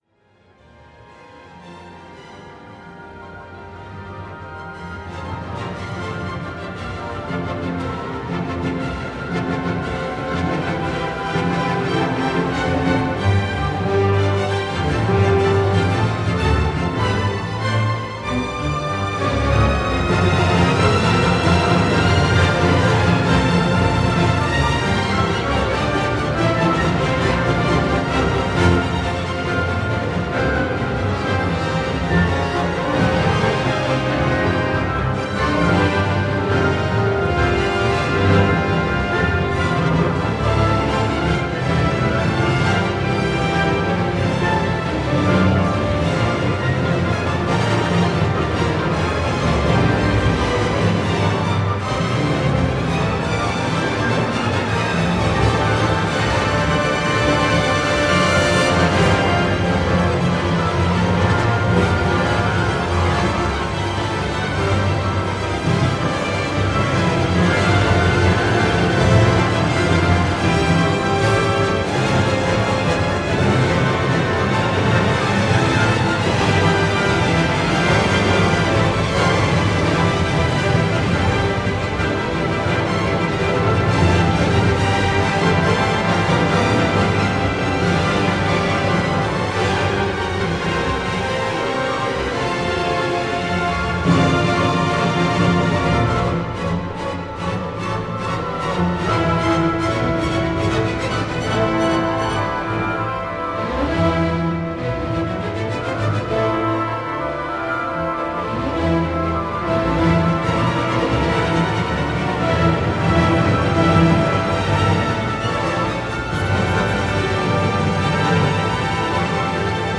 consists of nine symphonies
played simultaneously